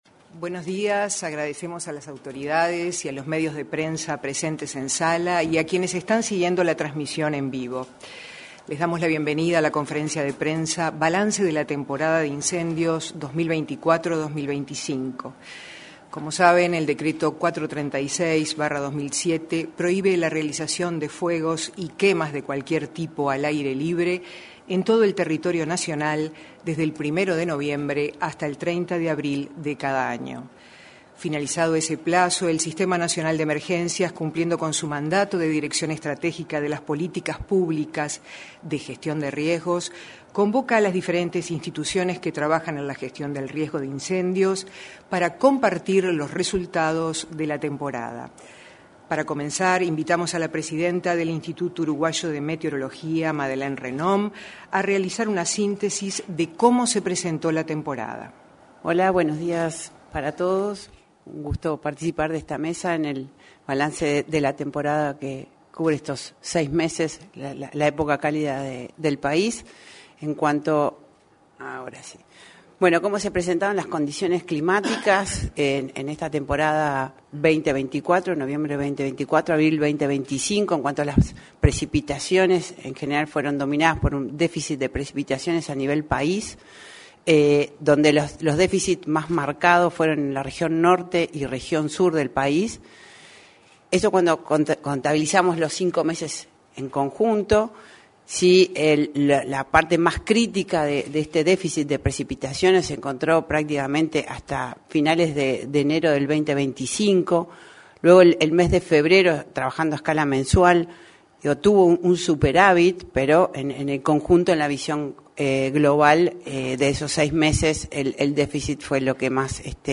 Este viernes 9, en la sala de prensa de la Torre Ejecutiva, se realizó la presentación del balance de la temporada de incendios forestales y de campo.